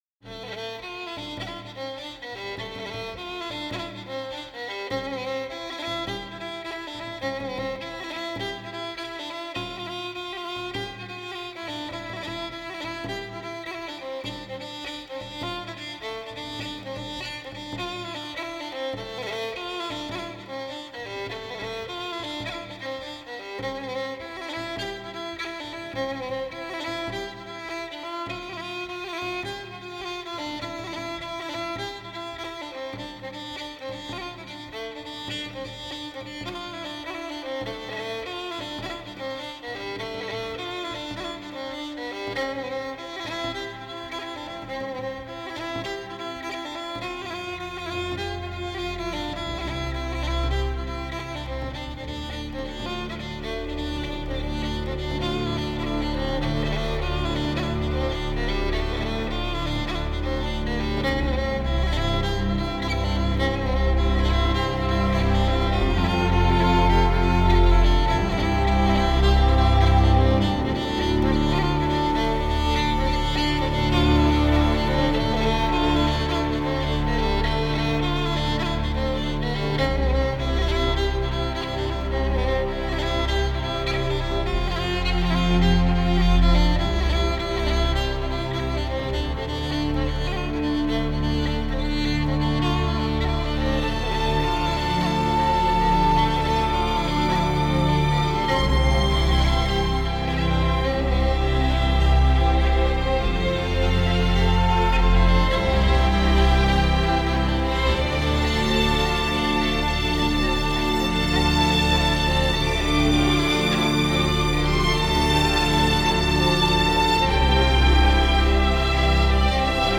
موسیقی بیکلام موسیقی متن